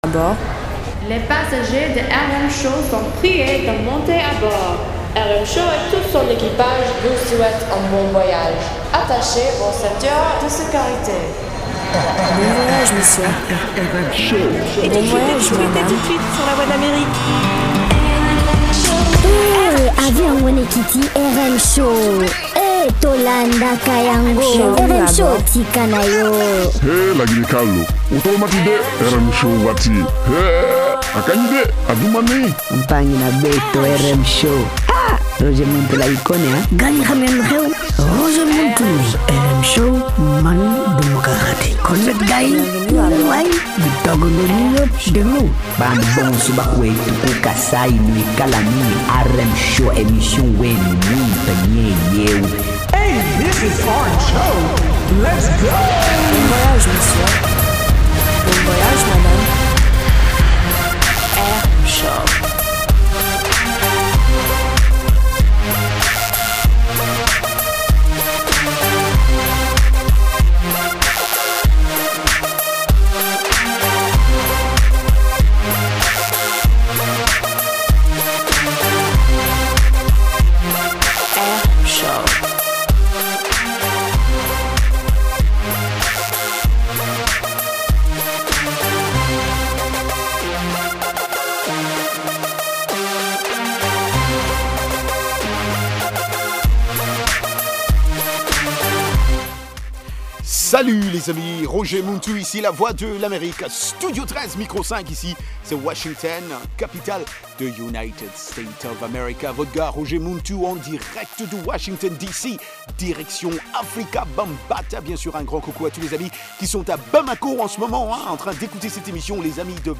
Palmarès des chansons à la mode, en rapport avec les meilleures ventes de disques aux Etats-Unies